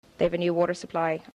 WATER with intervocalic flap (non-local Dublin speaker)
WATER_with_intervocalic_flap.mp3